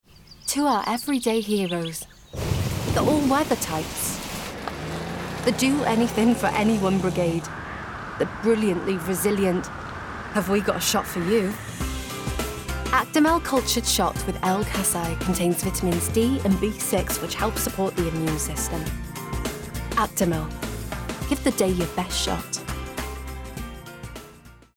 20/30's Welsh,
Expressive/Bright/Playful
Commercial Showreel Morrisons Vitality Homesense